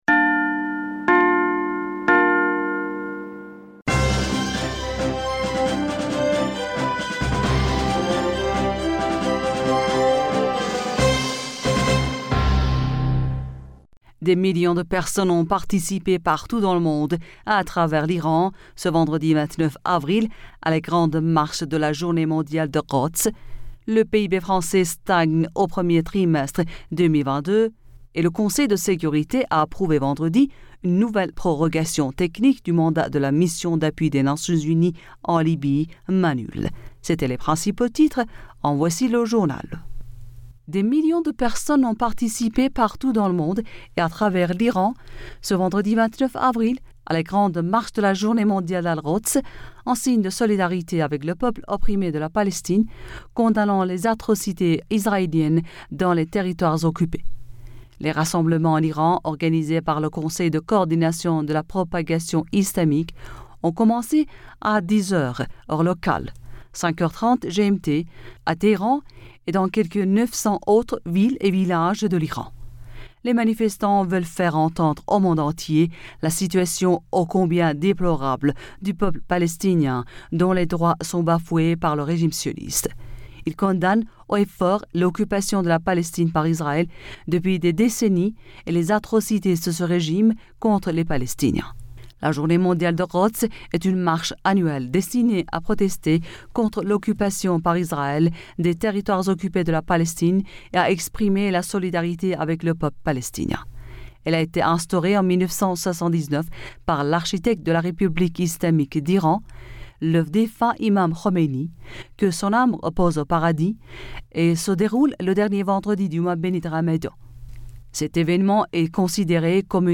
Bulletin d'information Du 30 Avril 2022